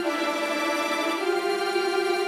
Index of /musicradar/gangster-sting-samples/105bpm Loops
GS_Viols_105-EG.wav